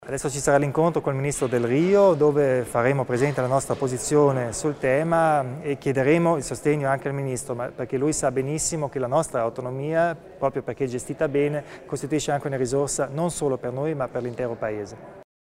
Il Presidente Kompatscher illustra i prossimi passi a difesa delle specificità dell'Autonomia altoatesina